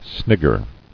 [snig·ger]